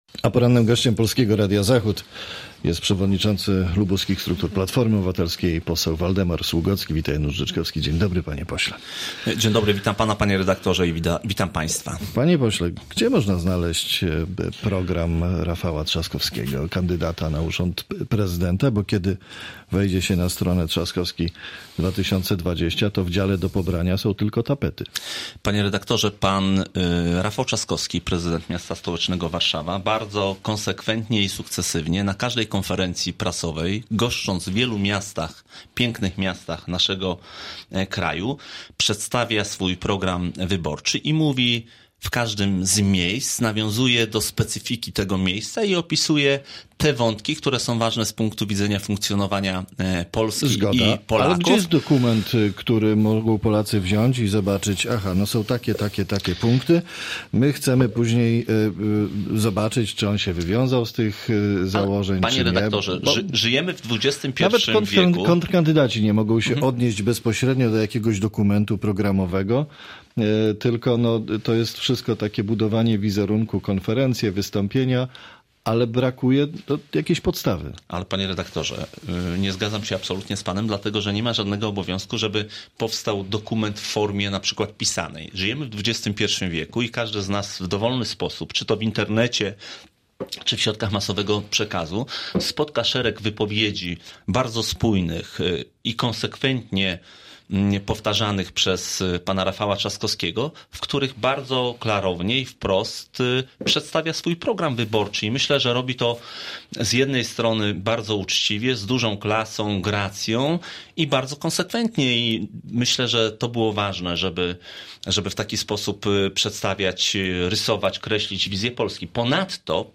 Z posłem PO, przewodniczącym partii w regionie rozmawia